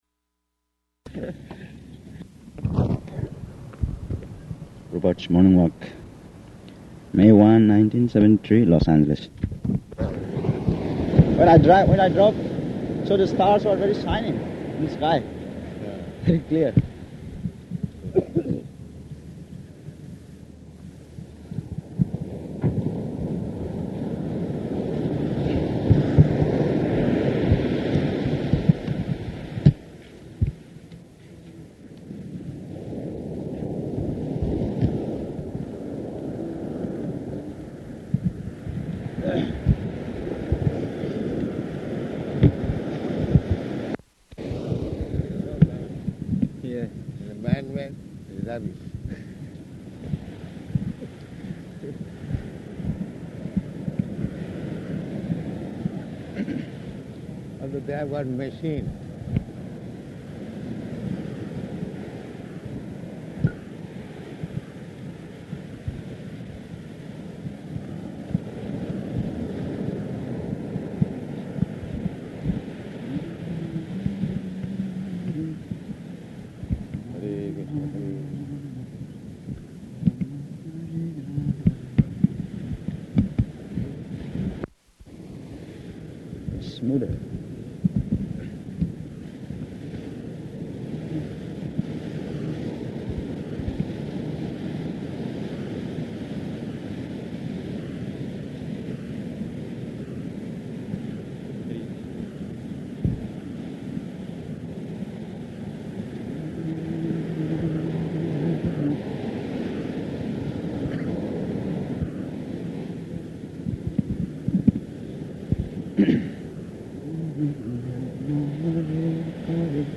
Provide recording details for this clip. -- Type: Walk Dated: May 1st 1973 Location: Los Angeles Audio file